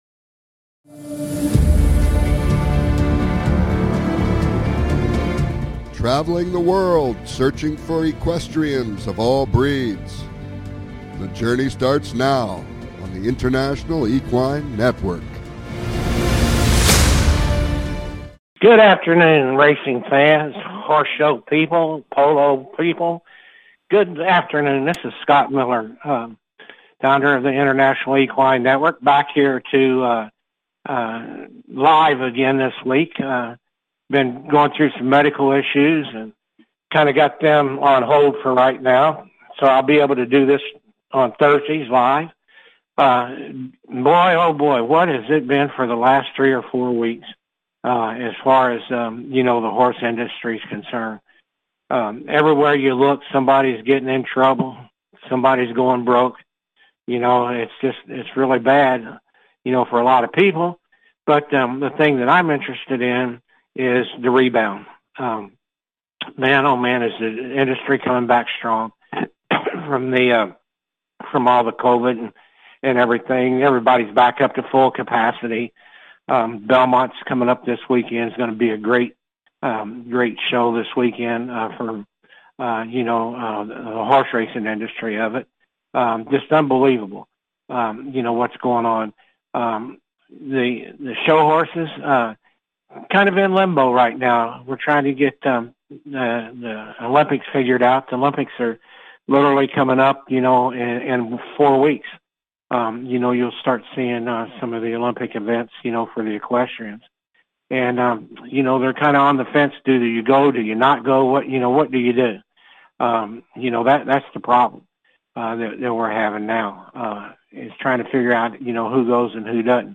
This show will bring you the inside scoop! Calls-ins are encouraged!